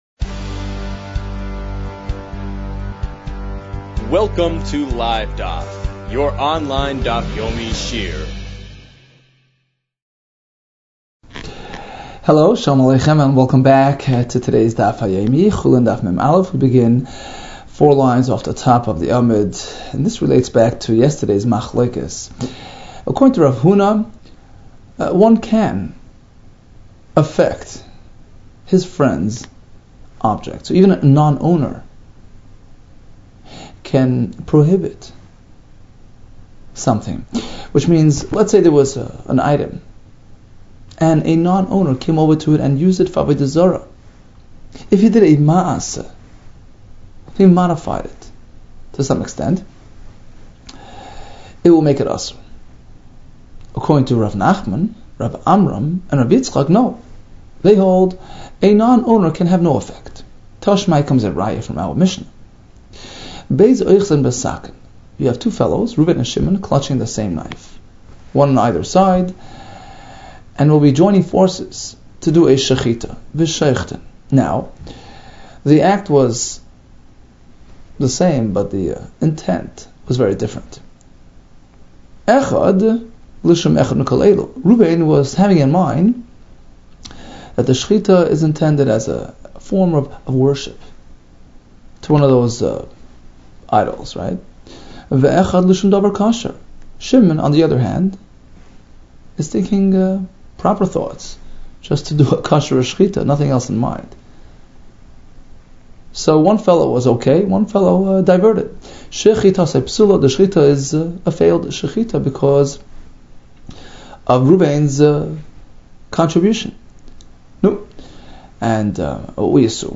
Chulin 40 - חולין מ | Daf Yomi Online Shiur | Livedaf